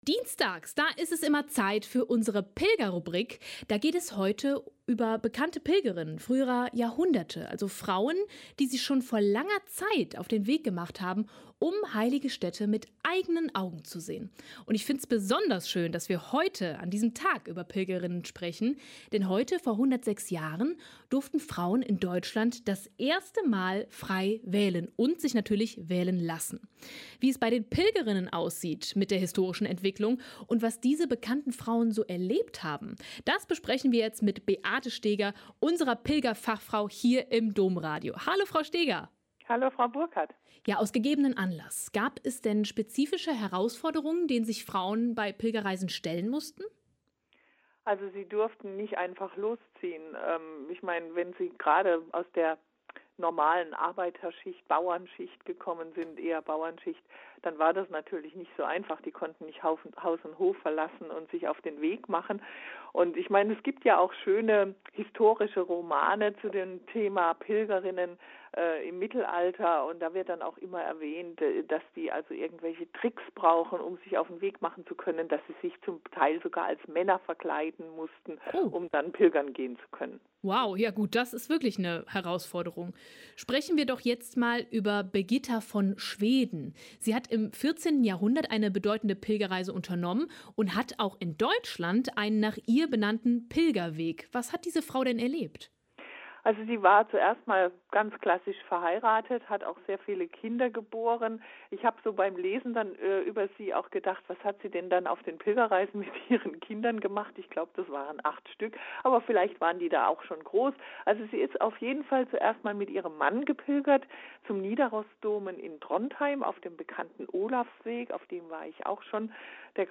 Pilgerexpertin erzählt Geschichten berühmter Pilgerinnen